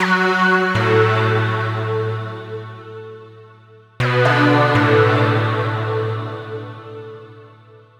TSNRG2 Lead 025.wav